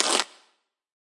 sounds from my bedroom » coin added to coins (Freeze)
描述：sounds recorded and slightly modified in Ableton
标签： bedroom processed random abstract items
声道立体声